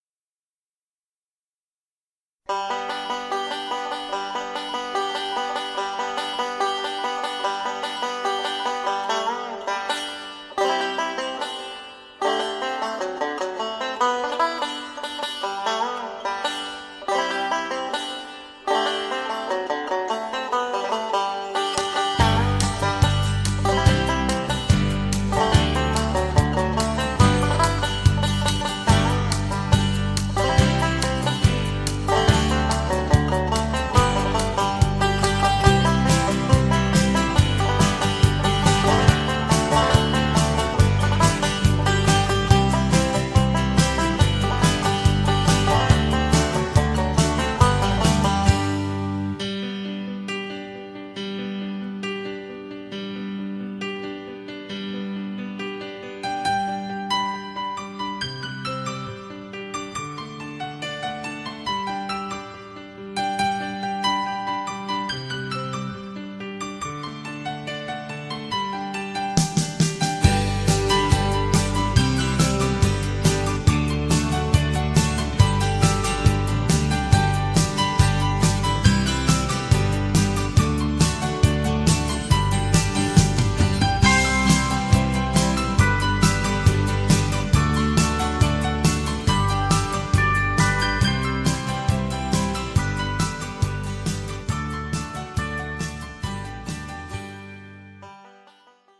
5-string banjo